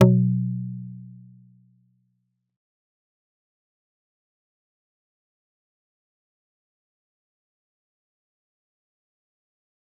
G_Kalimba-B2-mf.wav